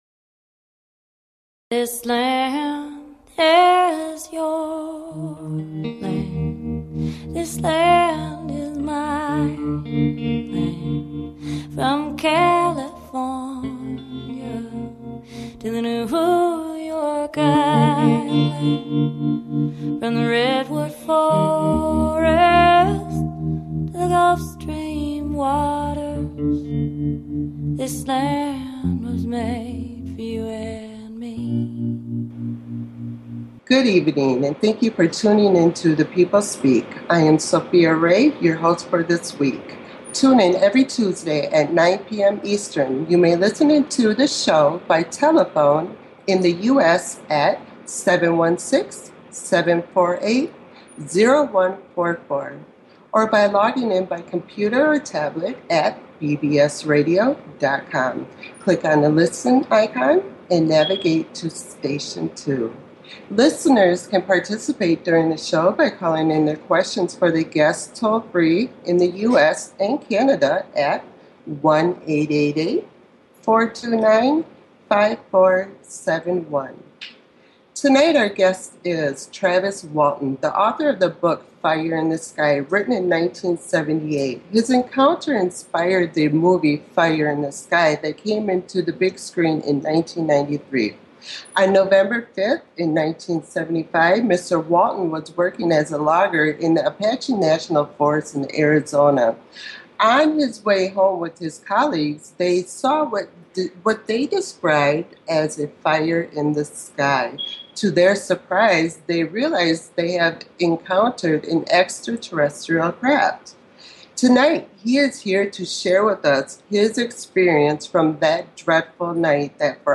Talk Show Episode
Guest, Travis Walton